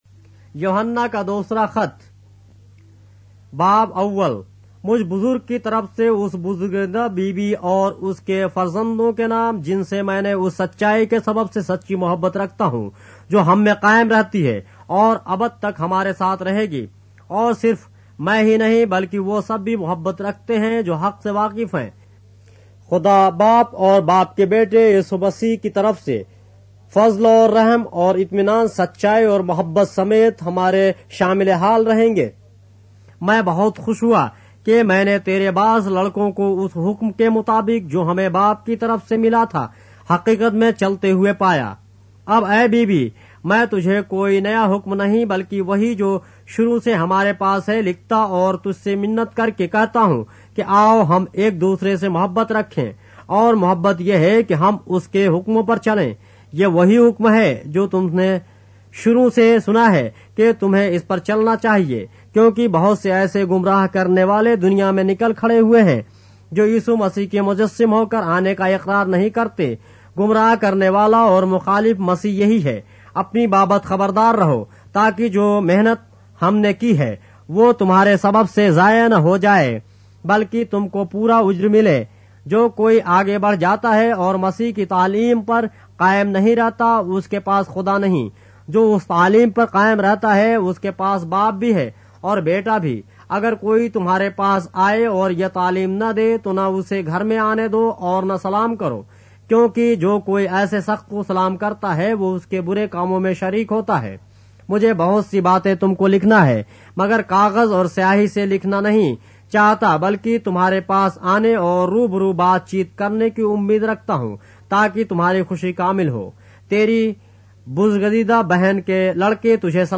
اردو بائبل کے باب - آڈیو روایت کے ساتھ - 3 John, chapter 1 of the Holy Bible in Urdu